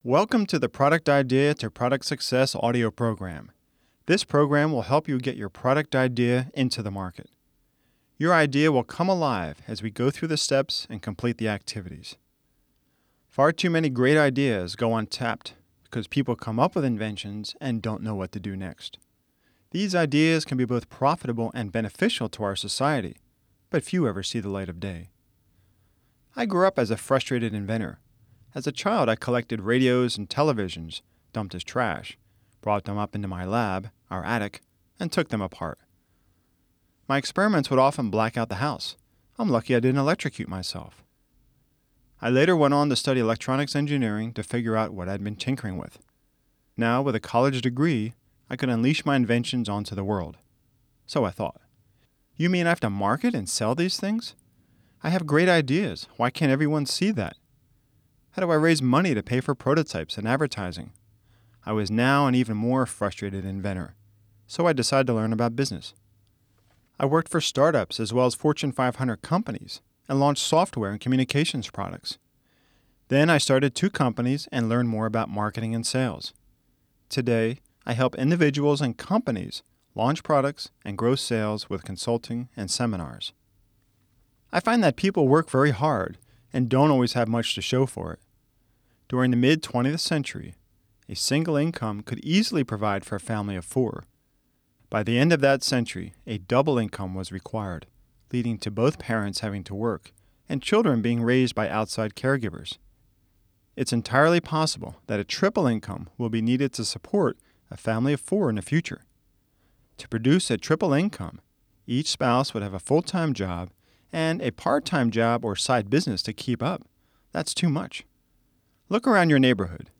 Product Idea to Product Success Audio Book